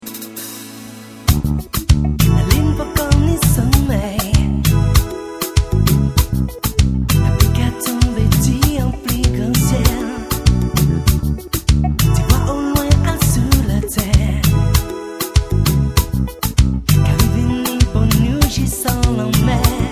Chant
Basse
Guitares
Claviers